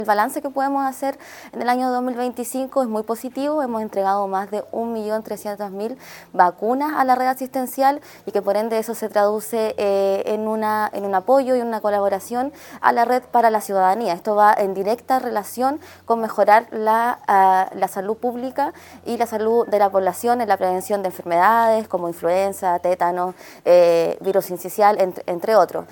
Javiera Ceballos, seremi (s) de Salud, hizo un balance positivo de las entregas que se han realizado a la red asistencial durante el año.